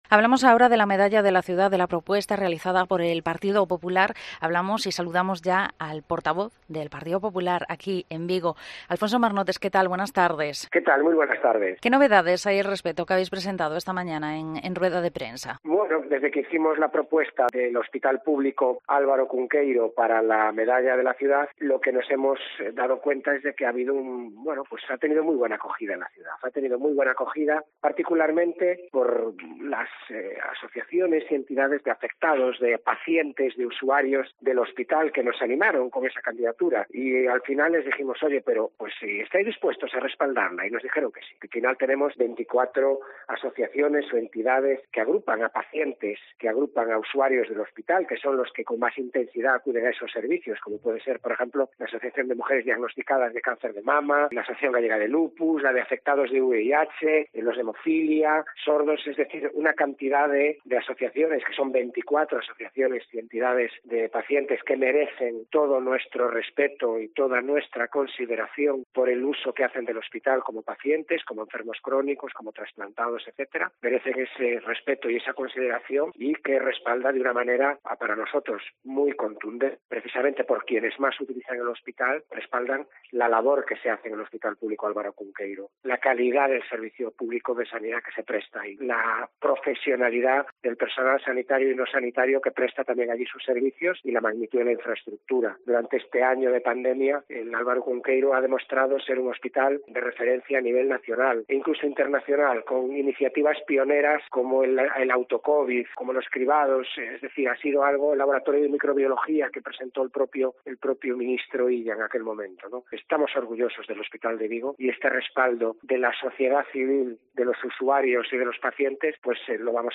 AUDIO: Hablamos sobre este asunto con el portavoz popular en el Concello de Vigo, Alfonso Marnotes.
ENTREVISTA